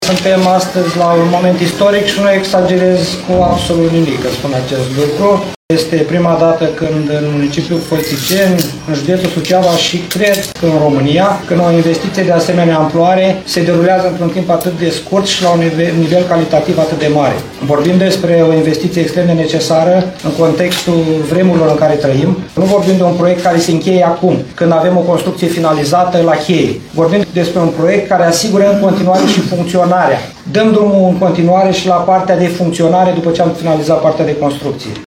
Primarul CĂTĂLIN COMAN a precizat că noua creșă are o capacitate de 90 locuri.